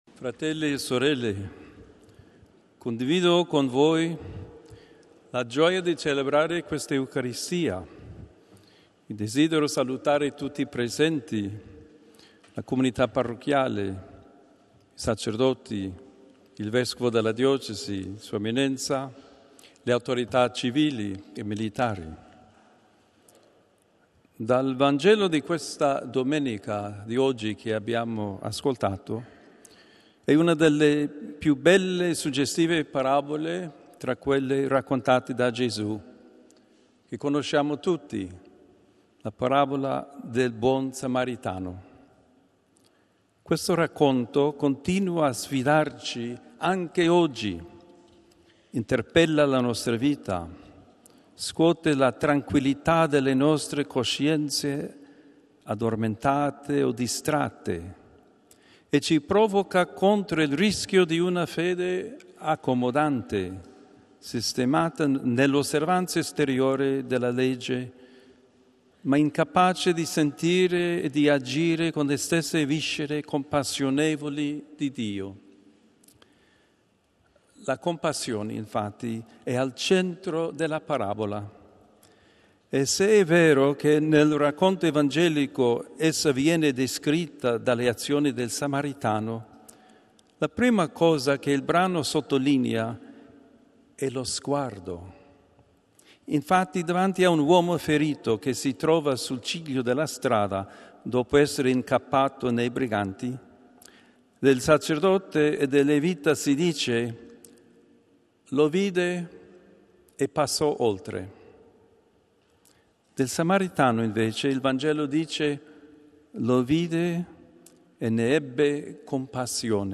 HOMELY BY CARD. CZERNY
FROM ST. PETER'S SQUARE, HOLY MASS PRESIDED BY CARD. MICHAEL CZERNY, S.J., PREFECT OF THE DICASTERY FOR PROMOTIING INTEGRAL HUMAN DEVELOPMENT, ON THE OCCASION OF THE JUBILEE OF THE WORLD OF VOLUNTEERING